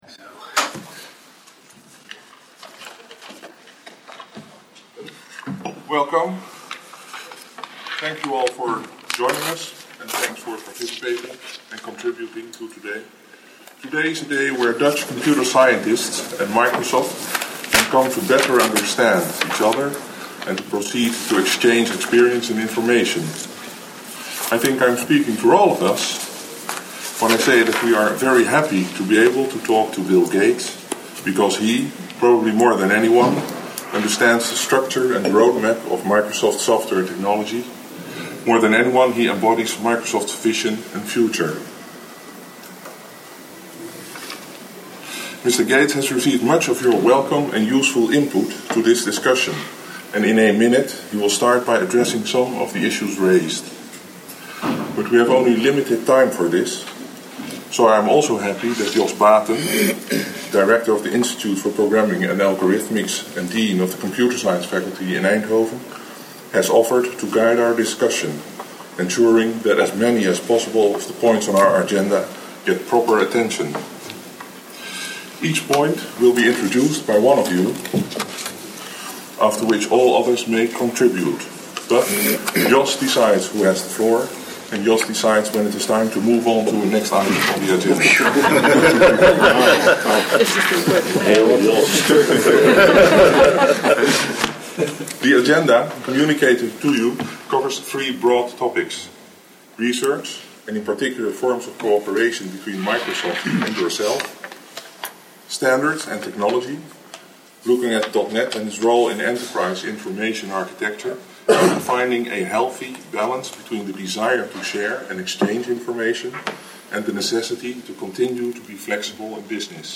Van de bijeenkomst zijn geluidsopnames gemaakt, en die zijn onlangs zo goed en zo kwaad als dat ging, ontdaan van ruis, en andere ongerechtigheden. Het resultaat is nu goed te verstaan.